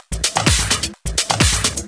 Index of /VEE/VEE Electro Loops 128 BPM
VEE Electro Loop 459.wav